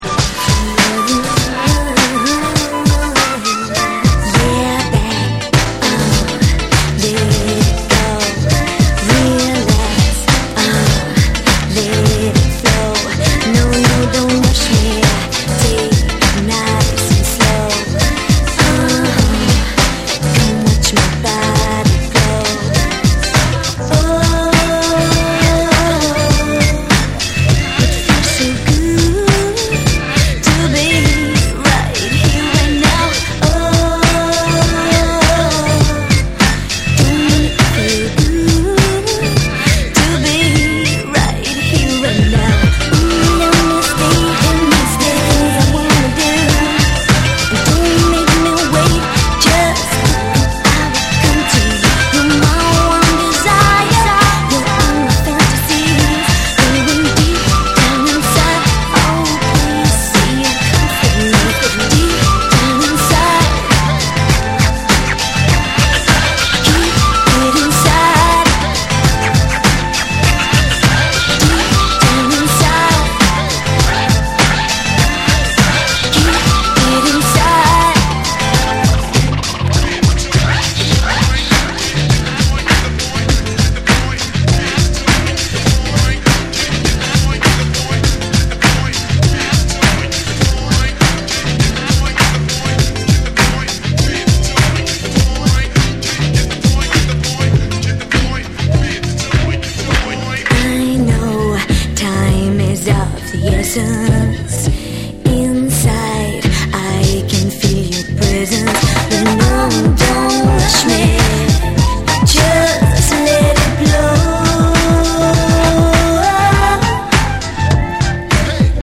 エレクトロ、ファンク、ブレイクビーツがスモーキーに溶け合うタイトル曲
BREAKBEATS